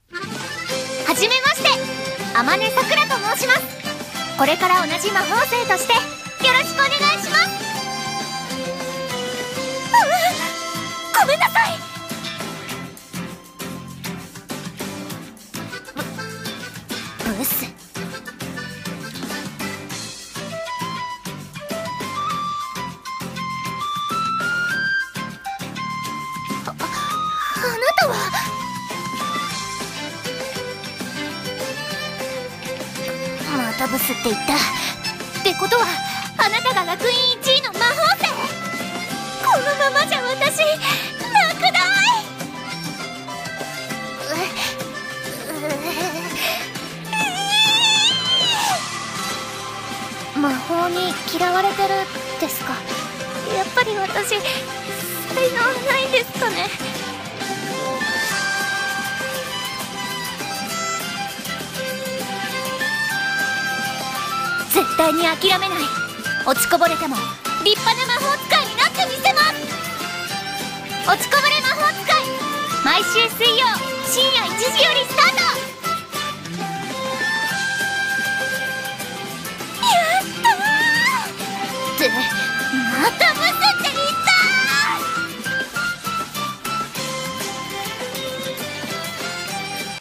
【CM風声劇】落ちこぼれ魔法使い【掛け合い】